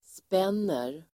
Uttal: [sp'en:er]